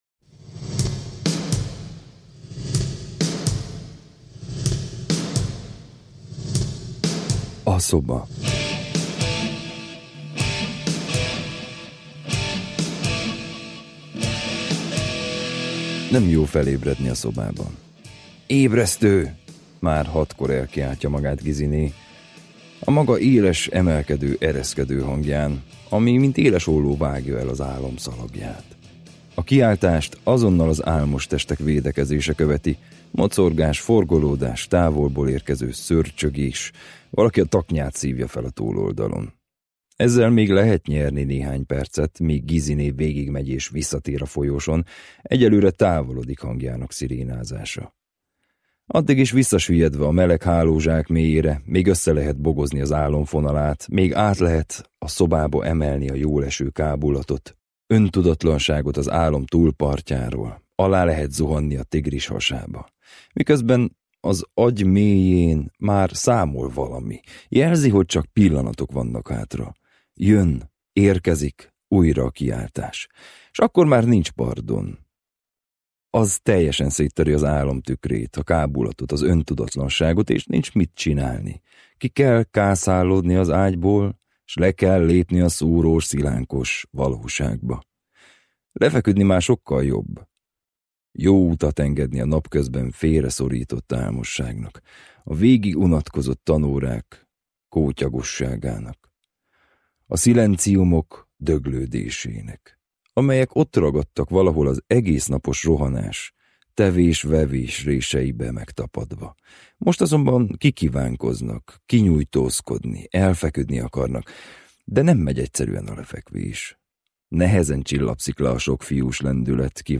Az élő ház című regényének hangoskönyv változata